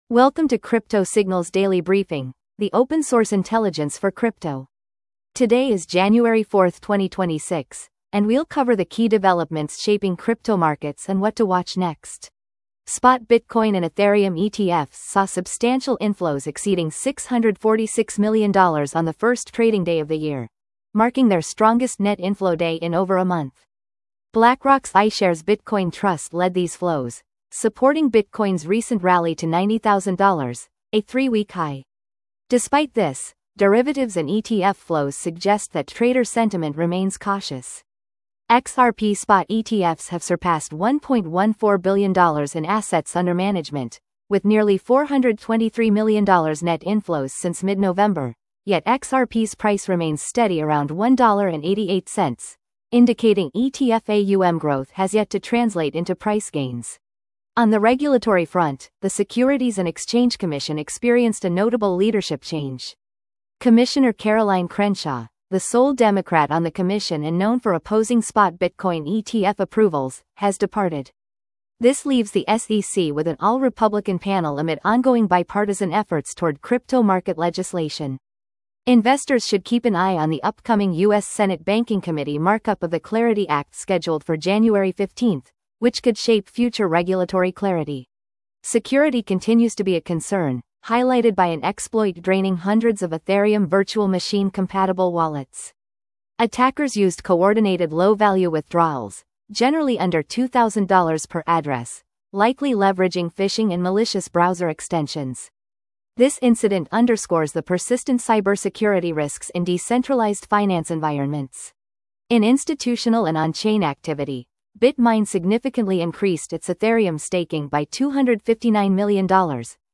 Crypto Signals Daily market brief